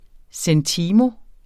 Udtale [ sεnˈtiːmo ]